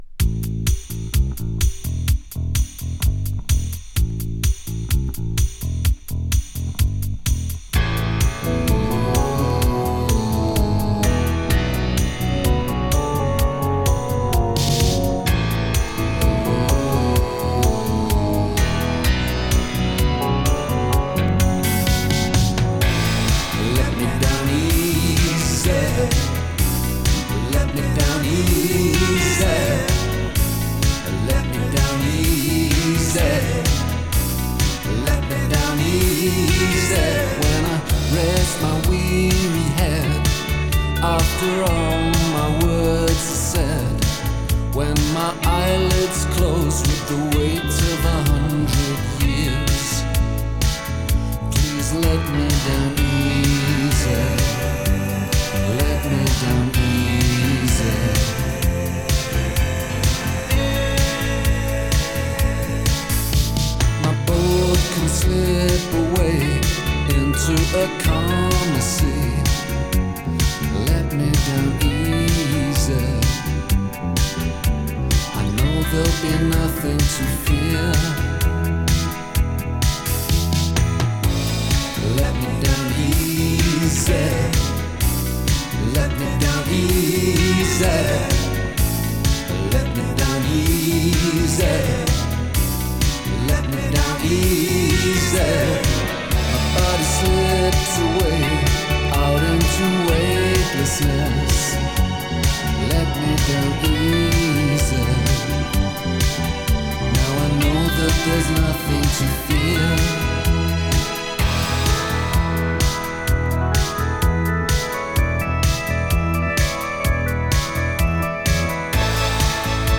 【NEW WAVE】
ニューウェイヴ・ポップ！